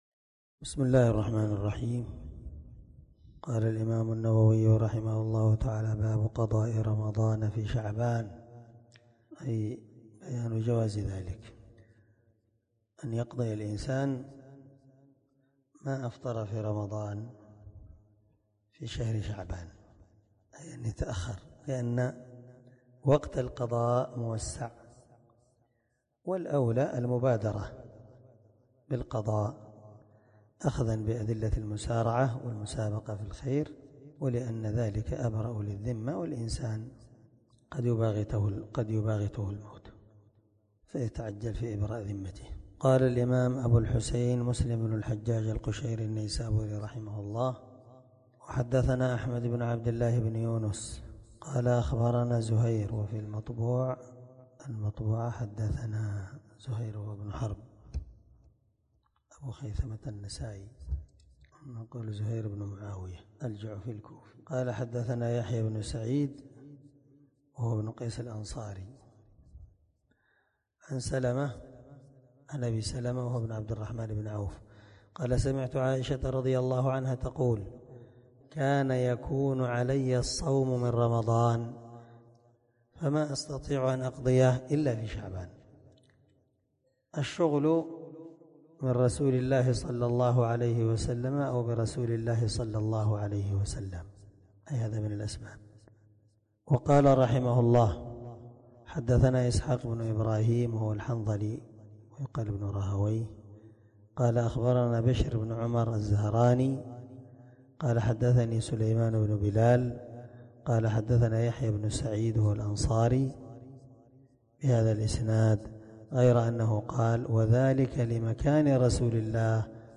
سلسلة_الدروس_العلمية
دار الحديث- المَحاوِلة- الصبيحة.